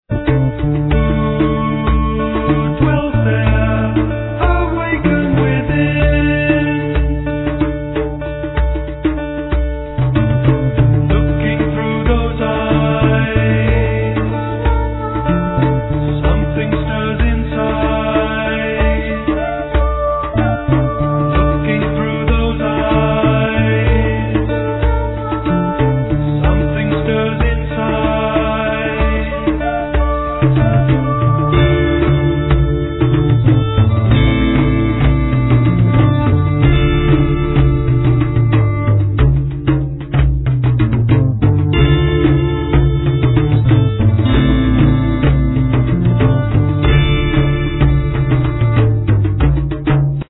Recorder
Oboe d'amore
Piano, Guitar
Voice, Violin